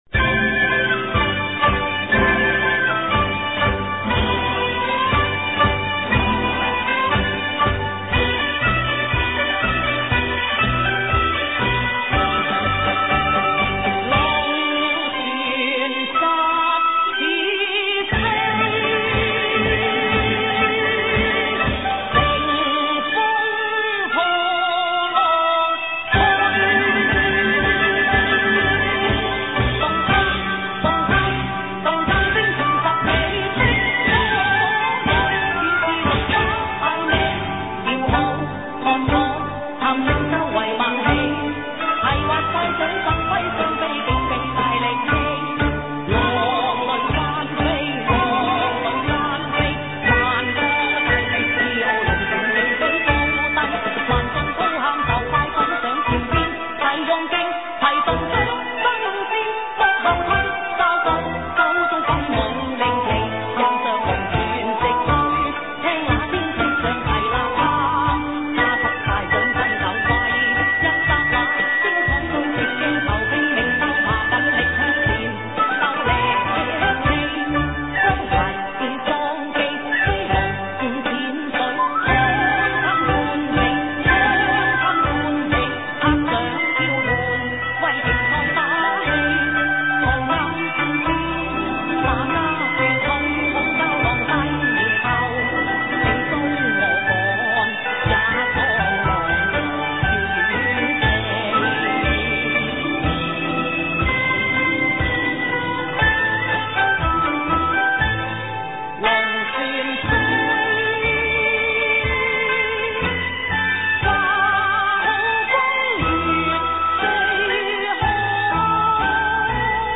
歌唱部份，是同一主題新的填詞。和旋律貼切配合。